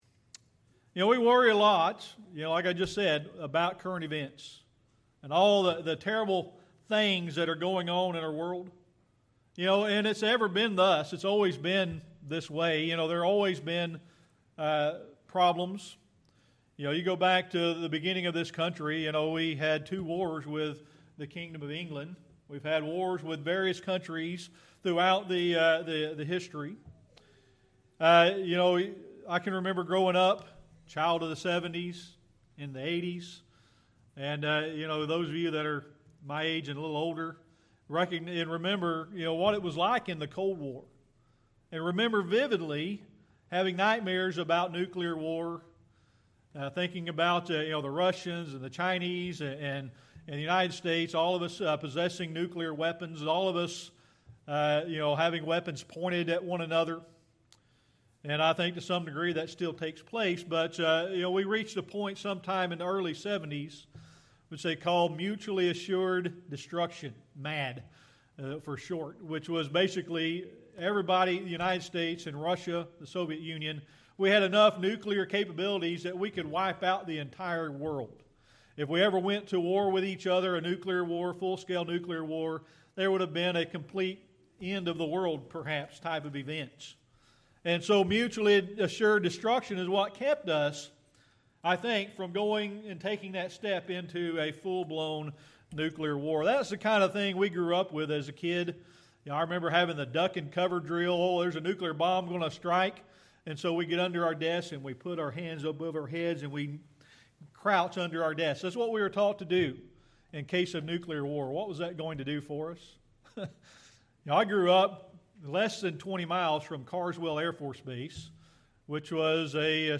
1 Corinthians 1:10 Service Type: Sunday Morning Worship We worry a lot about current events and the things going on in our world.